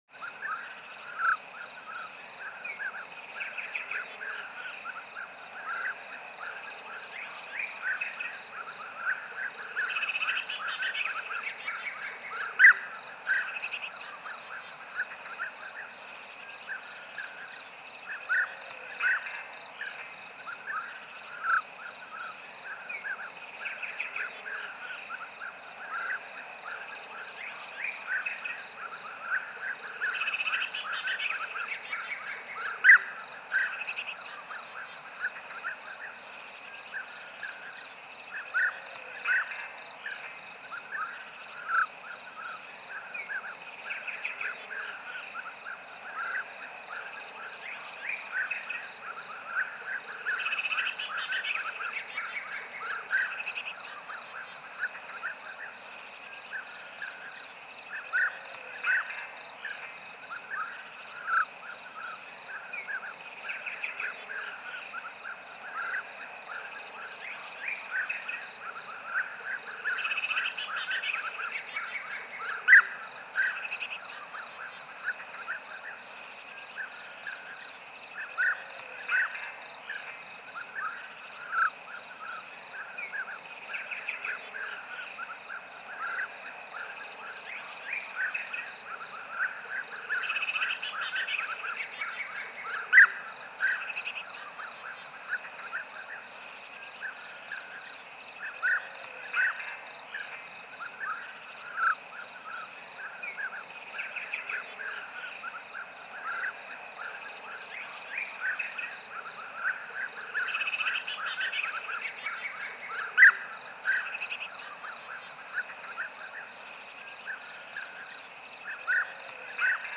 دانلود صدای پرنده 12 از ساعد نیوز با لینک مستقیم و کیفیت بالا
جلوه های صوتی
برچسب: دانلود آهنگ های افکت صوتی انسان و موجودات زنده دانلود آلبوم صدای پرندگان و حشرات از افکت صوتی انسان و موجودات زنده